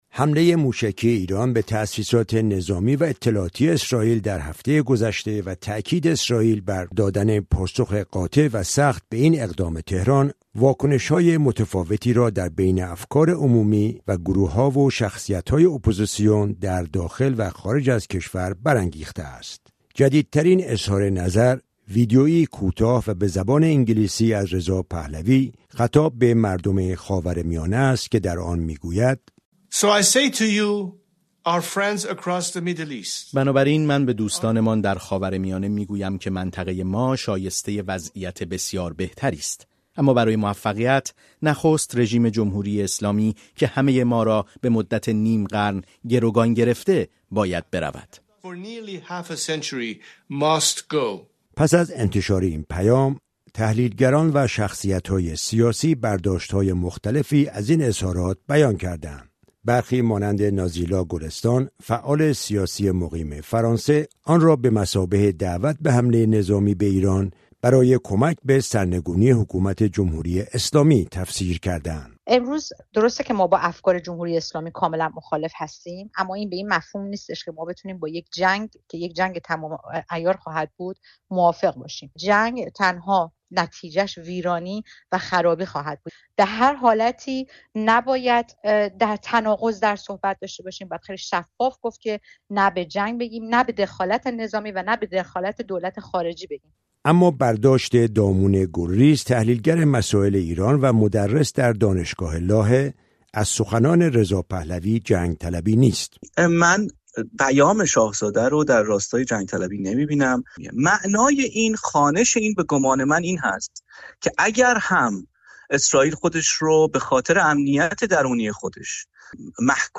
گزارش رادیویی «ایرانیان درباره جنگ چه می‌گویند؟»